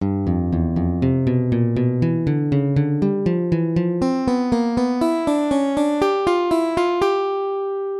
ドミナントコード・７thコード　エンクロージャー
次は、ルート音のGを抜いたもの。